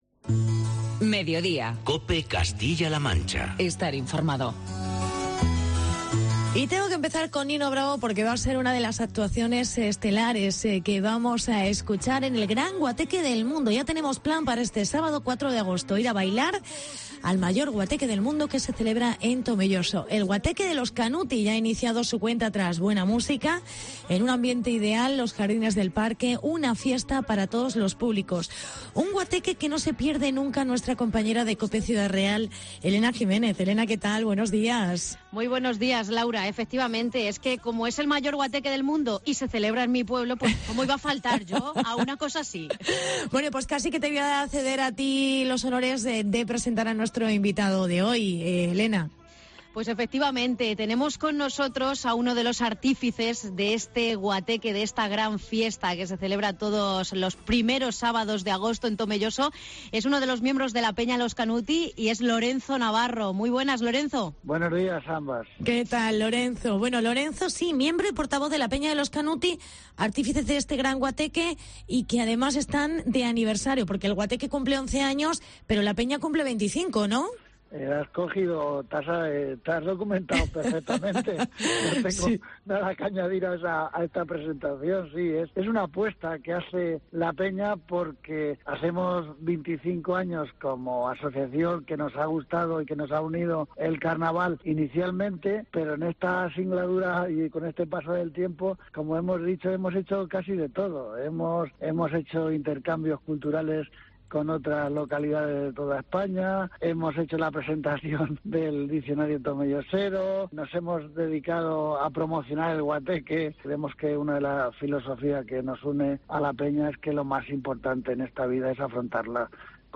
Hablamos con el alcalde: Lorenzo Navarro